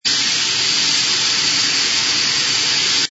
sfx_steam01.wav